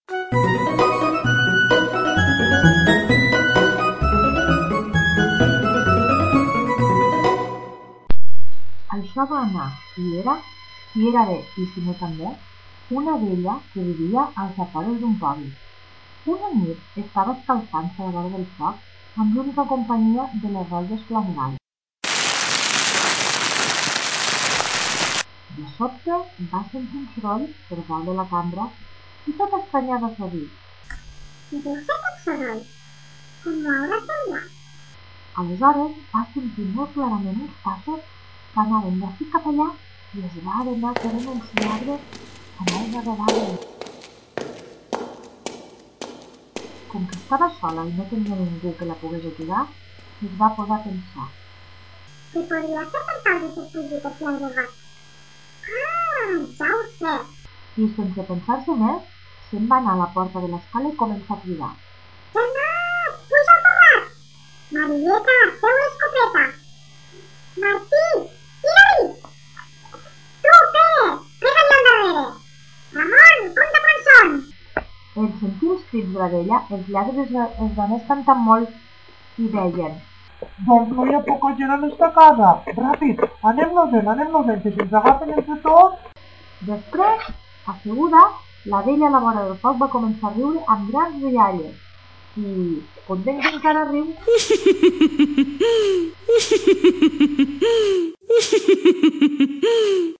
Per a mi ha estat una experiència interessant gravar un conte i posar-li efectes! Jo, que pensava que m’havia de comprar un micròfon ja que ni sabia que l’ordinador el portava integrat.